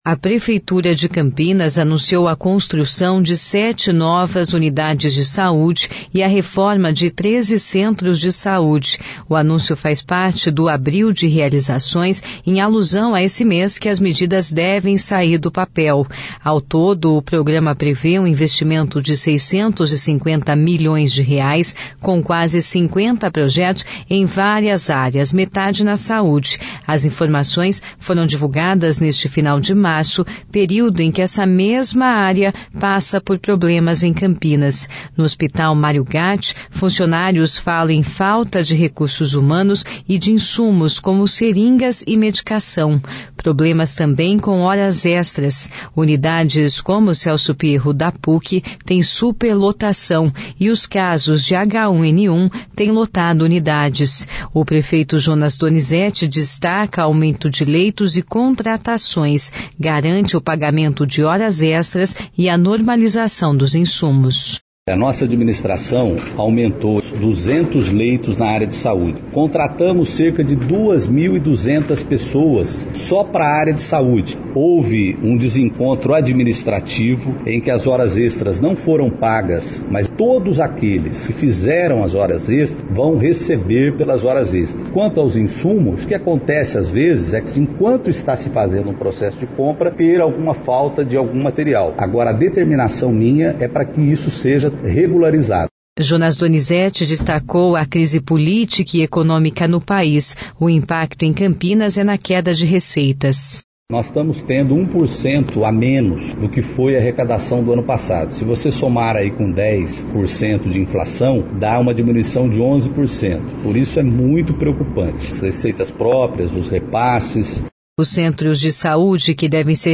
O prefeito de Campinas, Jonas Donizette, destaca aumento leitos e contratações na cidade.